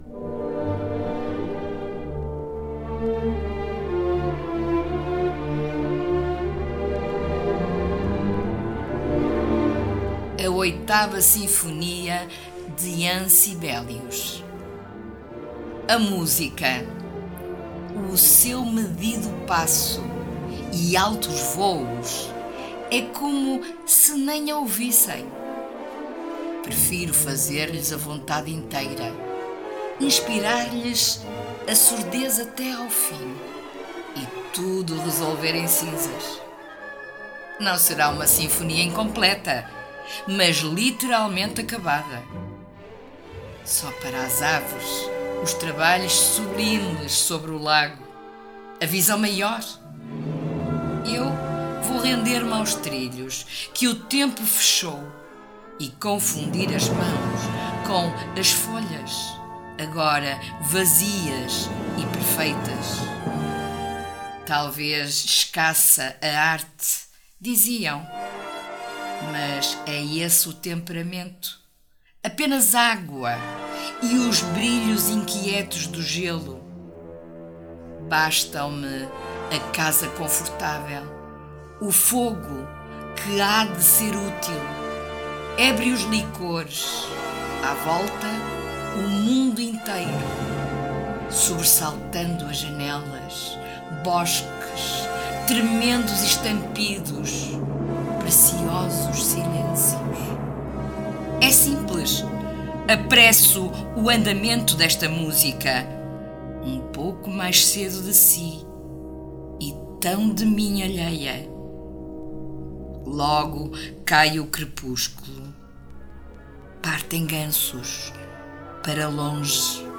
“A Oitava Sinfonia de Jean Sibelius”, de José Manuel Teixeira da Silva, in Os Pequenos Nós da Tempestade, ed. Língua Morta, abril de 2023, pp. 270-271. Música: 2.º movimento (Tempo andante, ma rubato) da 2.ª Sinfonia, em Ré maior, Op. 43, de Jean Sibelius.